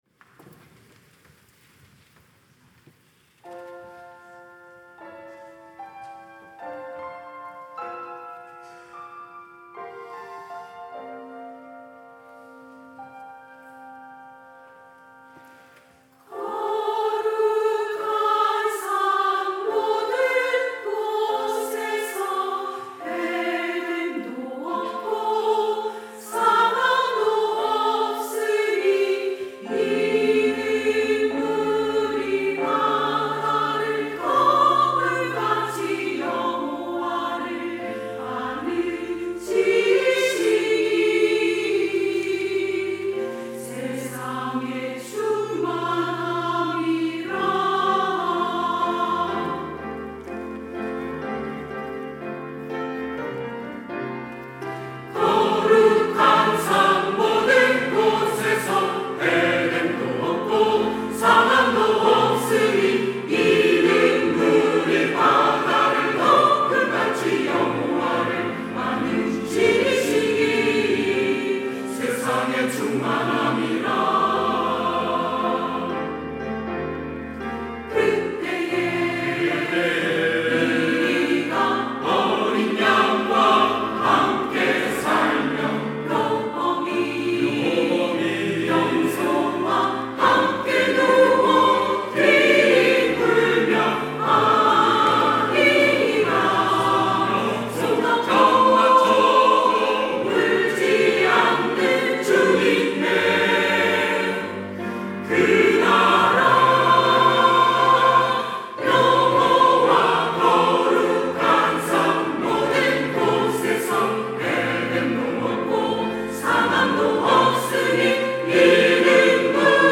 호산나(주일3부) - 거룩한 산
찬양대